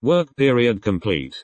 work-complete.mp3